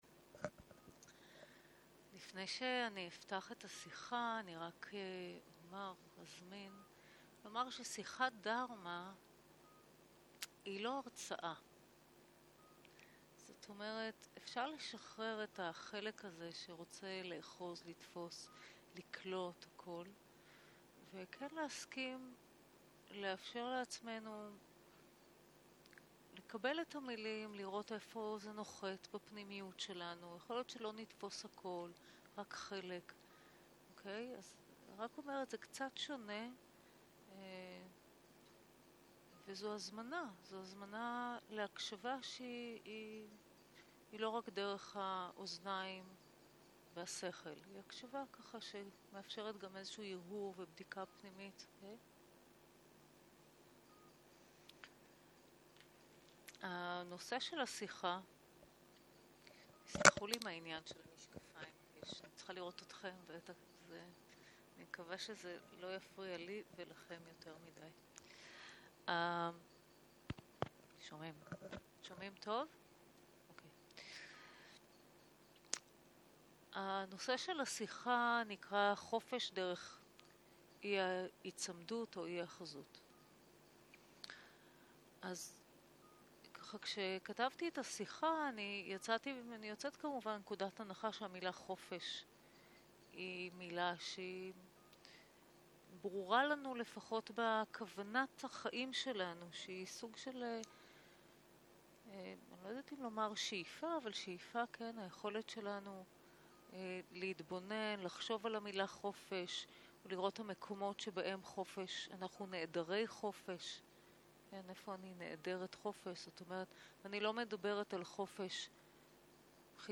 סוג ההקלטה: שיחות דהרמה
ריטריט יום העצמאות